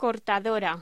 Locución: Cortadora
Sonidos: Hostelería